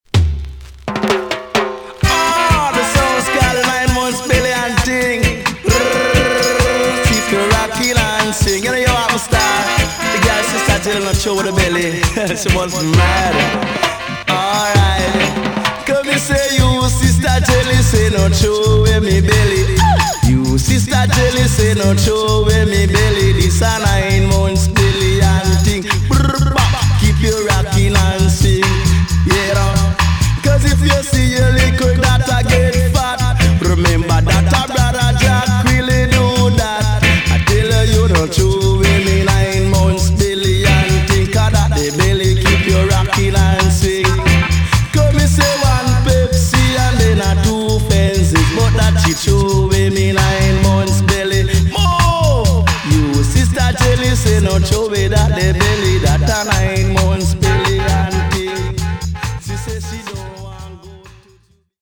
TOP >80'S 90'S DANCEHALL
EX- 音はキレイです。
1978 , UK , NICE DJ STYLE!!